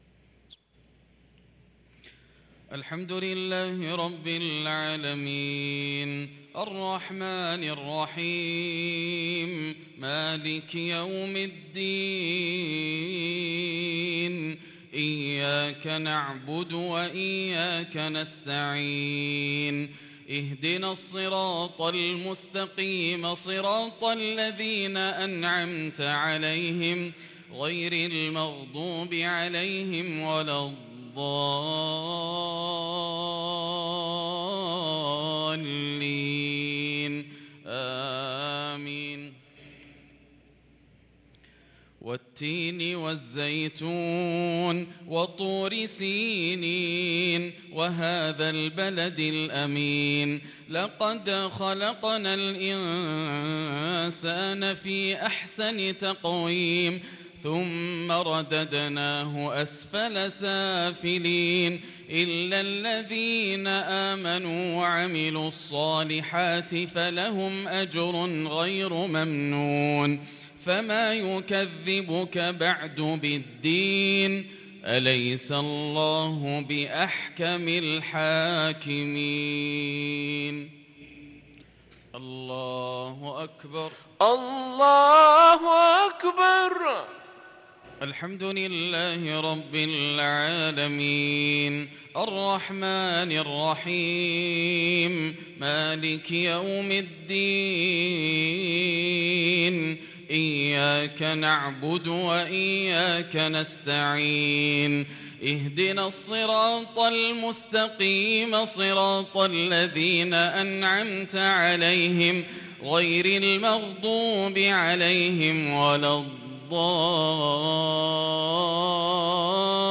صلاة المغرب للقارئ ياسر الدوسري 5 ذو القعدة 1443 هـ
تِلَاوَات الْحَرَمَيْن .